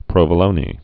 (prōvə-lōnē, prōvə-lōn)